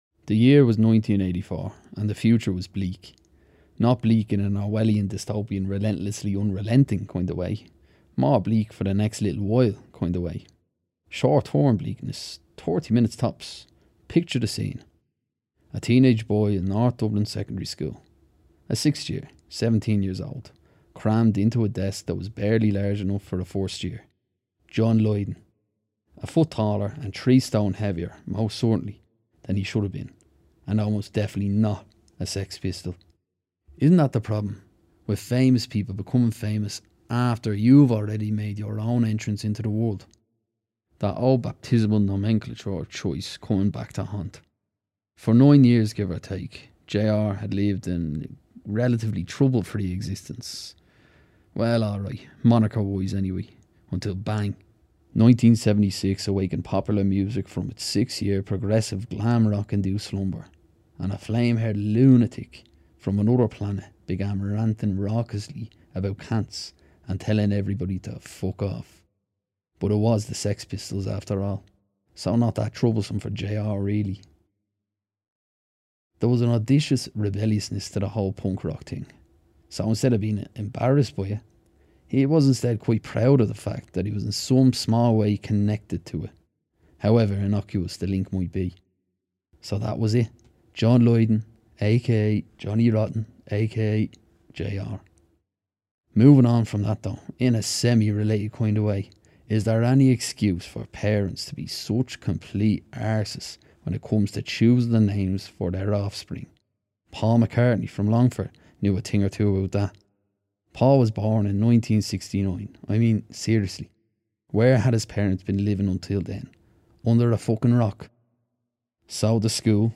I've a comedic style naturally but I can also do slower, more serious pieces when required.
irisch
Sprechprobe: Werbung (Muttersprache):